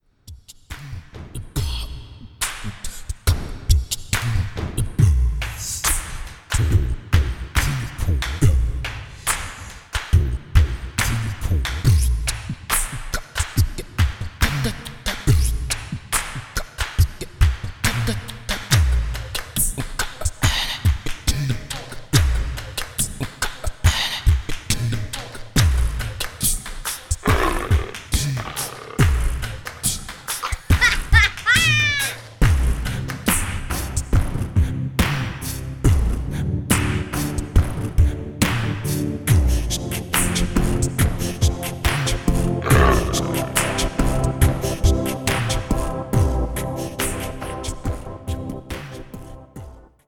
Genre: Musik (Kinder)